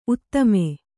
♪ uttame